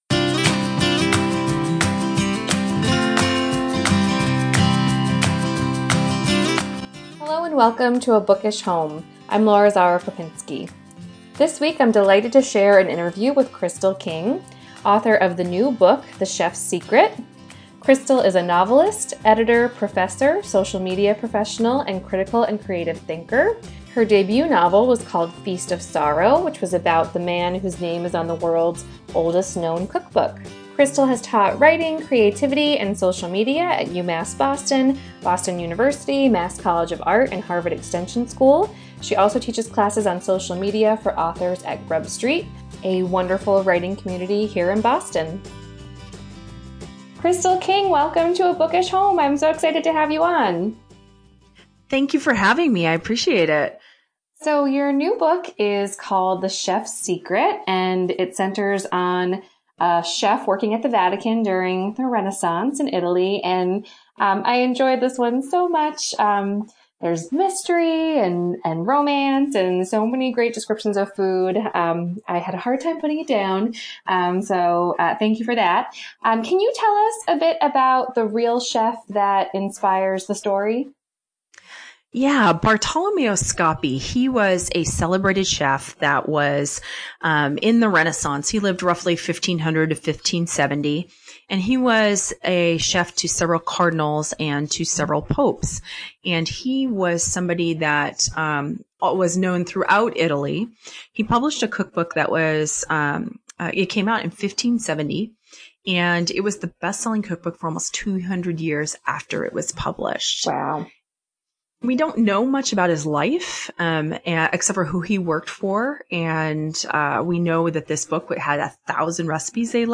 This week I’m sharing an interview